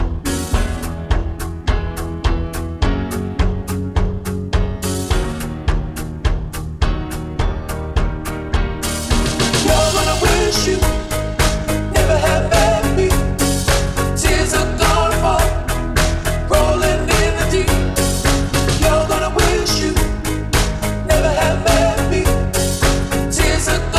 Four Semitones Down Pop (2010s) 3:48 Buy £1.50